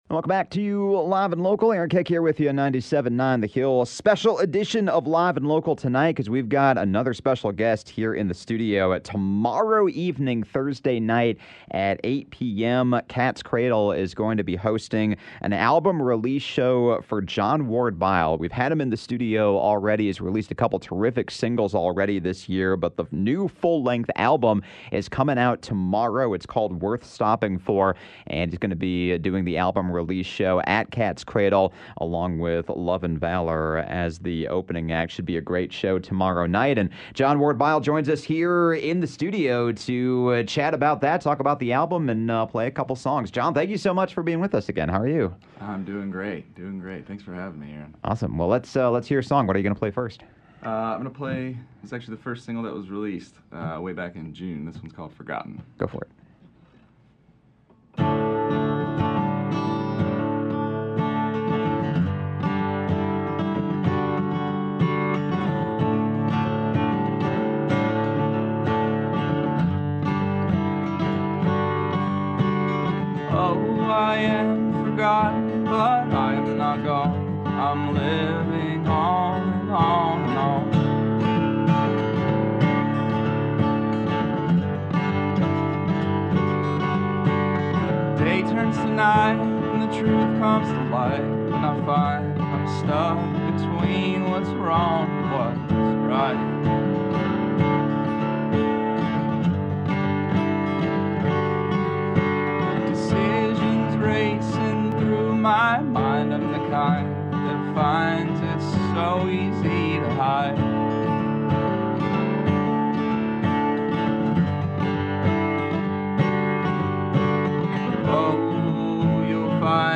folk-Americana singer-songwriter